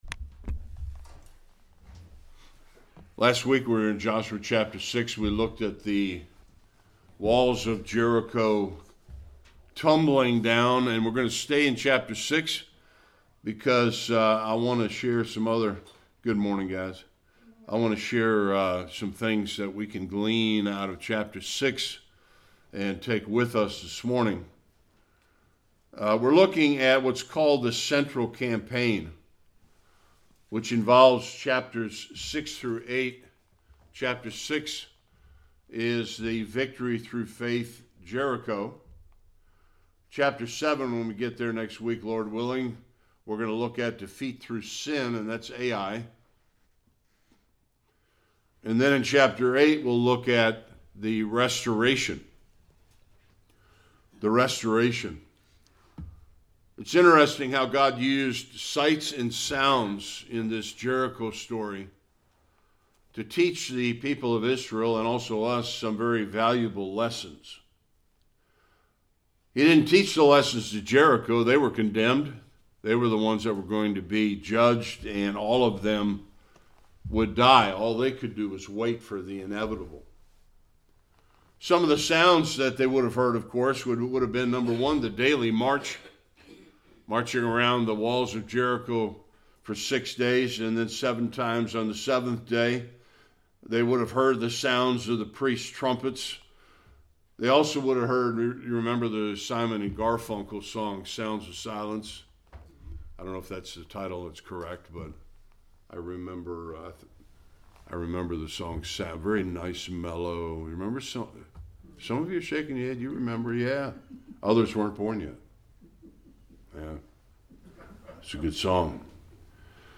Joshua 6 Service Type: Sunday School Israel learned some valuable lessons from the conquest of Jericho.